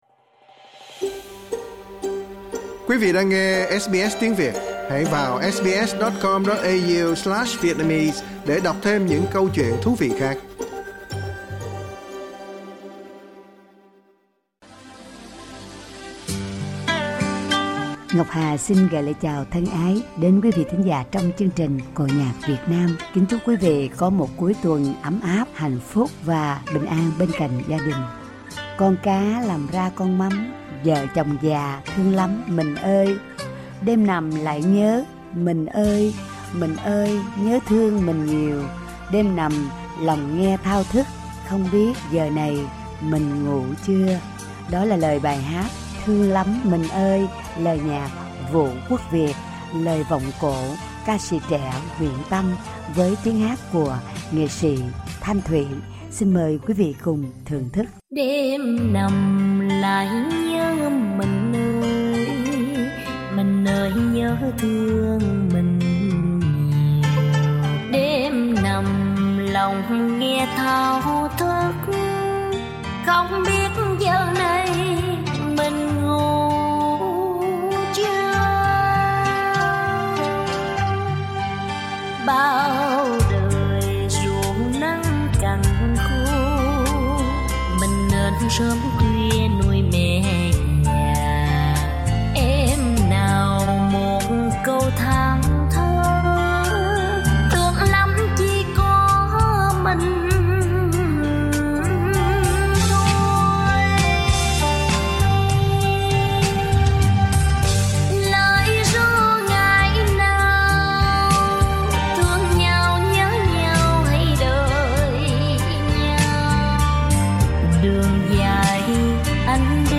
Tân Cổ
vọng cổ